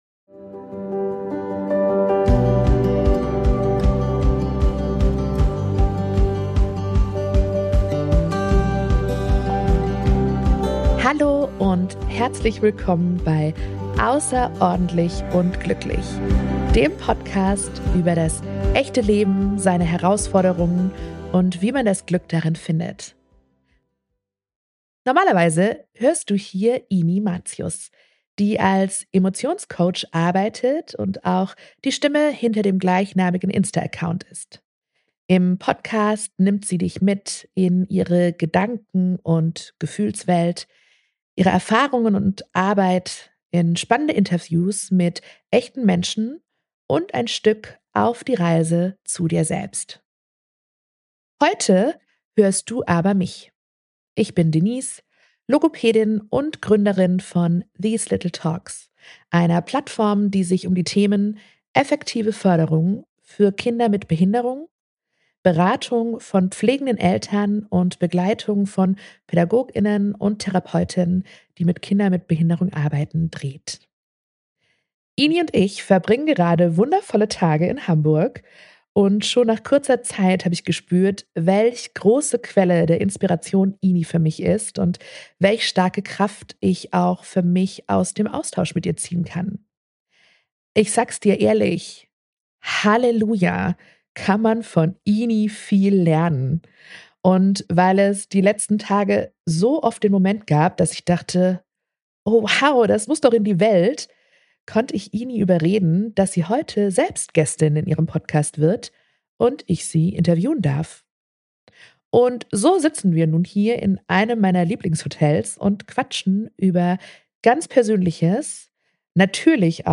Intuitiv und ehrlich geben wir Gedanken Preis und nehmen dich mit in Kopf und Herz einer unvergesslichen Freundinnenzeit in Hamburg.